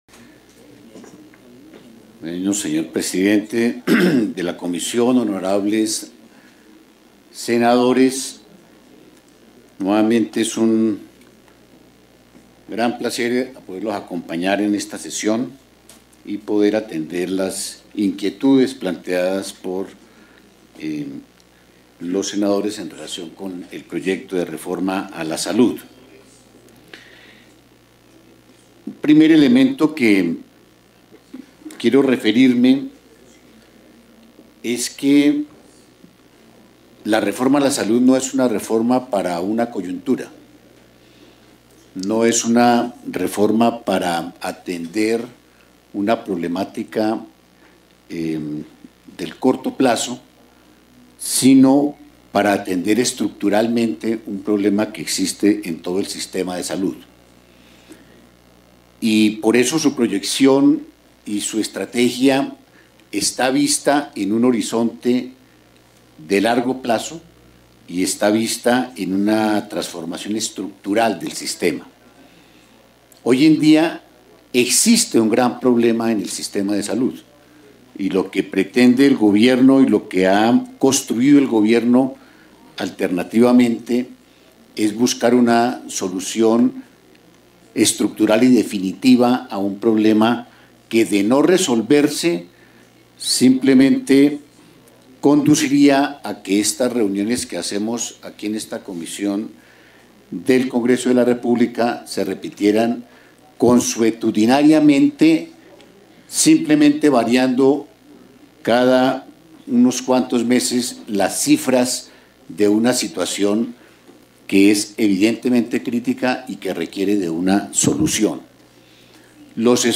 Intervención del Ministro en la Comisión 7 de Senado | Reforma a la Salud
intervencion-del-ministro-en-la-comision-7-de-senado-reforma-a-la-salud-1